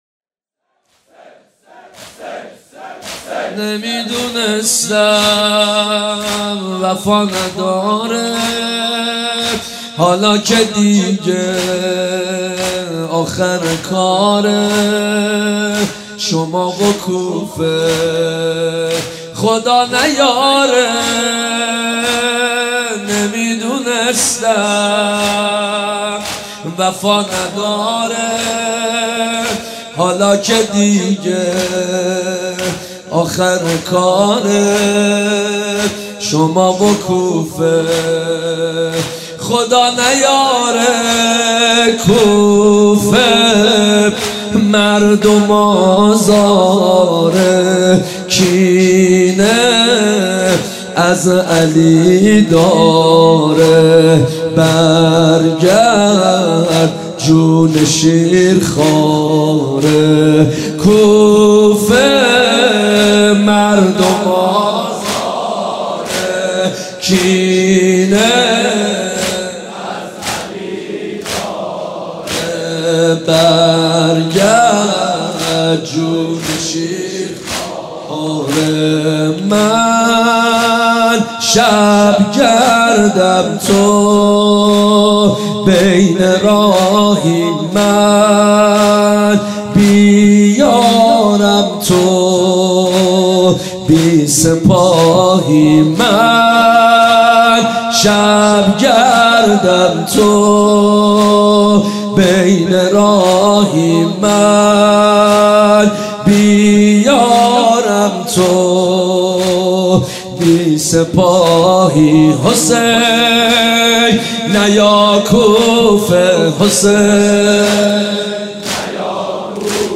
شب اول محرم الحرام 1394 | هیات مکتب الحسین اصفهان
نمی دونستم وفا نداره | زمینه | حضرت مسلم بن عقیل علیه السلام